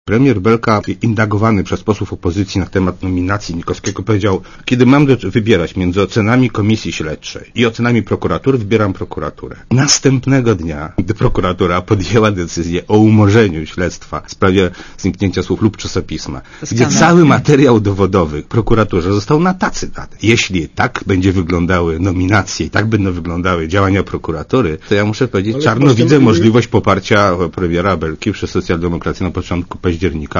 Czarno widzę możliwość poparcia premiera Marka Belki przez Socjaldemokrację na początku października - powiedział w programie Radia Zet "7 dzień tygodnia" wicemarszałek Sejmu Tomasz Nałęcz (SdPl).
Komentarz audio